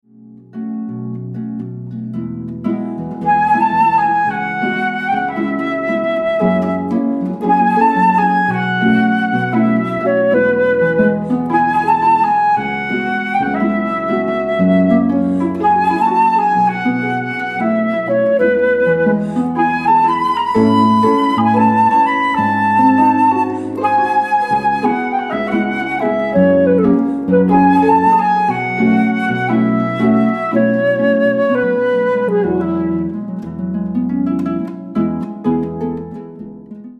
Jazz/Pop